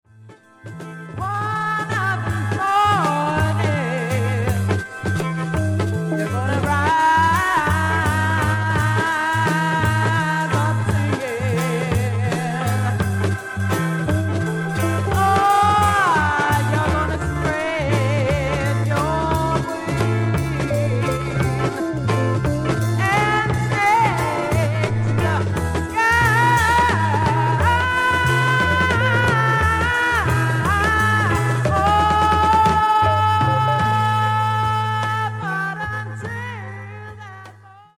SOFT ROCK / PSYCHEDELIC POP